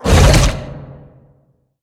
Sfx_creature_bruteshark_chase_os_01.ogg